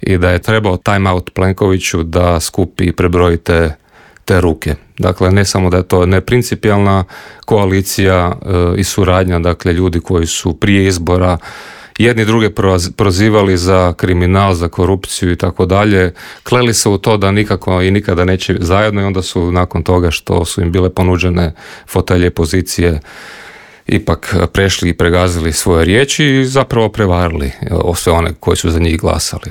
O svemu smo u Intervjuu Media servisa razgovarali sa saborskim zastupnikom SDP-a Mihaelom Zmajlovićem.